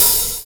DISCO 15 OH2.wav